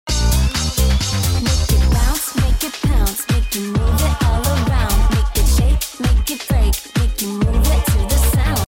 Disco Funk House Is Sounding Sound Effects Free Download